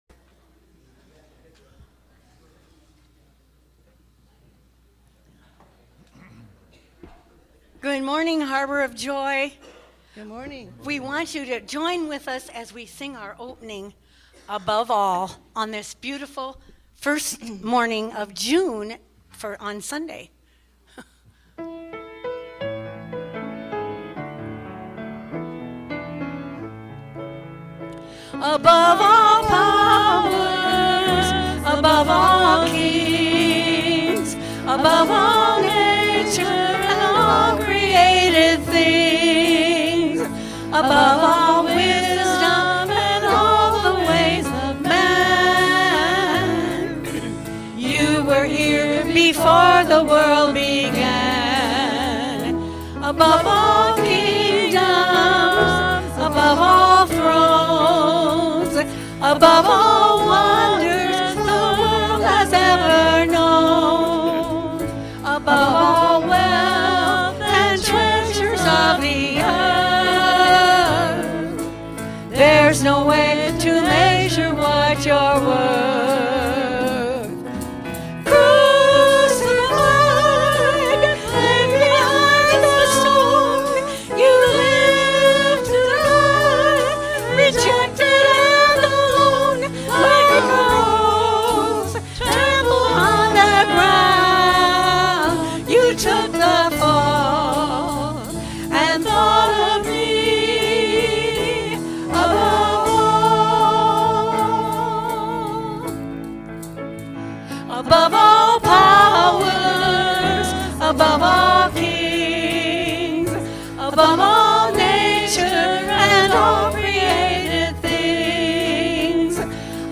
Worship_-June-2_-2024-Voice-Only.mp3